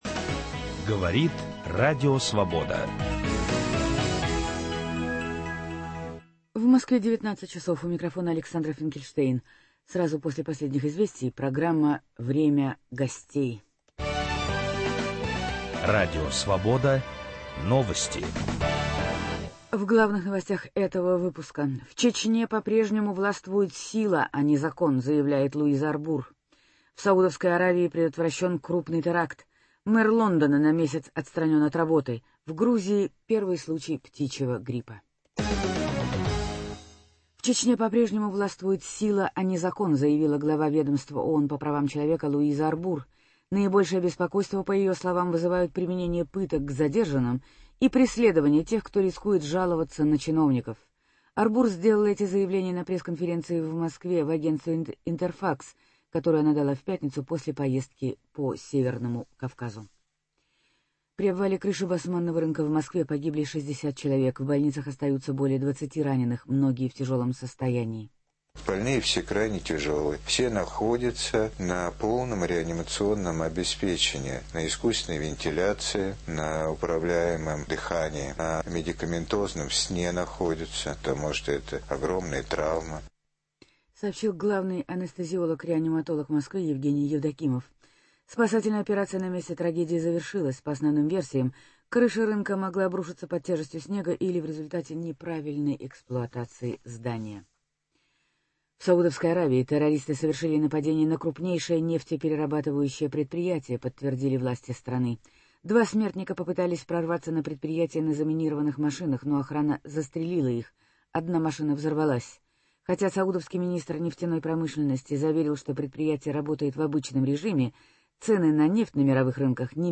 С кандидатом на пост президента Белоруссии Александром Милинкевичем беседует Виталий Портников